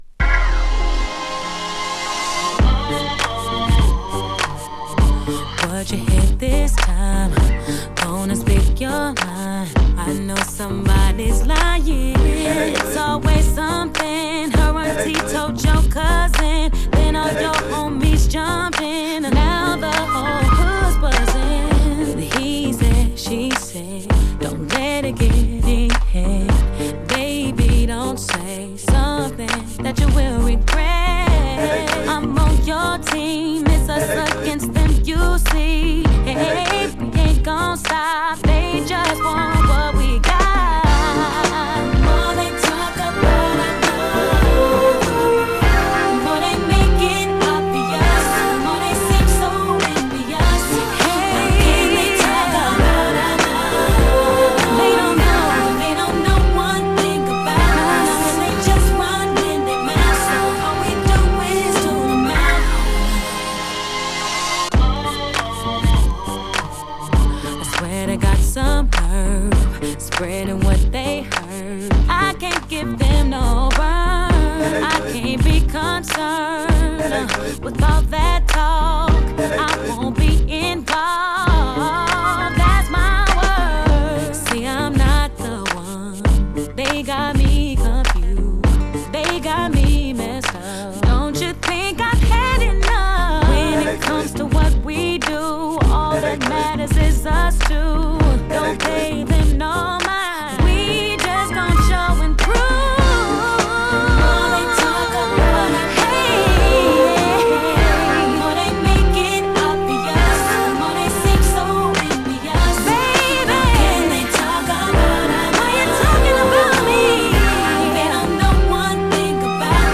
ハンド・クラップとループするベースラインが印象的な、ドラマティックな展開がぐっとくる2004年のクラブヒット